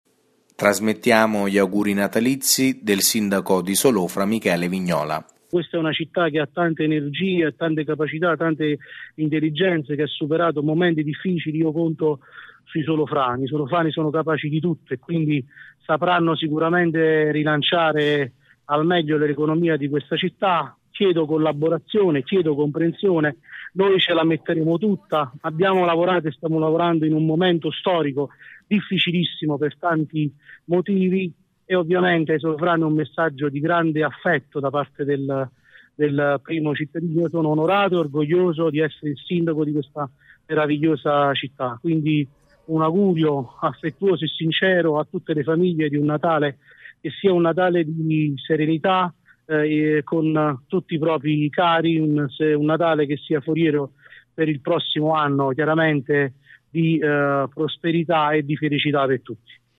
Il Sindaco di Montoro Bianchino, il Capogruppo di Maggioranza Lepre ed il Sindaco di Solofra Vignola, attraverso i nostri microfoni hanno augurato buone feste ai cittadini delle rispettive comunità.
Gli auguri del Sindaco di Solofra, Vignola:
Auguri-Natalizi-del-Sindaco-di-Solofra-Michele-Vignola-2015.mp3